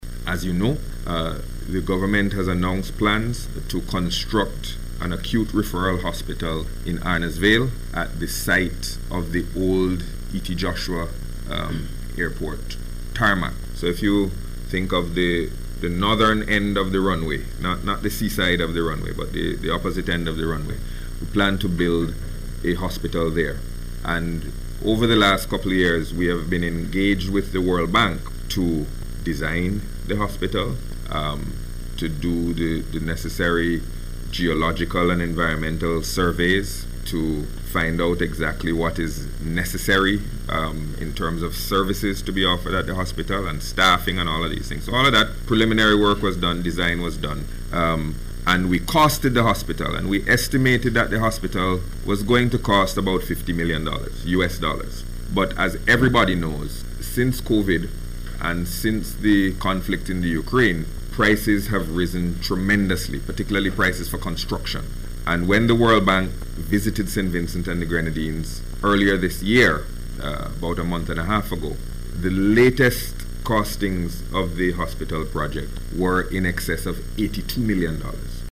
Speaking at today’s News Conference, Minister Gonsalves outlined the issues dealt with during meetings with officials of the World Bank.